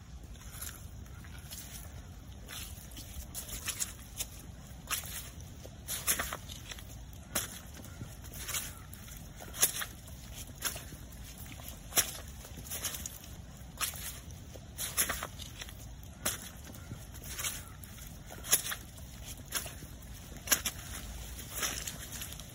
Tiếng Bước Chân đi trên đất Bùn lầy, nền đất Ẩm ướt (Sample 2)
Thể loại: Tiếng động
Description: Tiếng Bước Chân đi trên đất Bùn lầy, nền đất Ẩm ướt, tiếng bùn hút chân “xoạp”, nước văng “tõm”, dư vang ẩm sâu (Sample 2) tái hiện âm lẹp xẹp, lép nhép, bõm bõm, lộp bộp đặc trưng của bùn nhão, sền sệt.
tieng-buoc-chan-di-tren-dat-bun-lay-nen-dat-am-uot-sample-2-www_tiengdong_com.mp3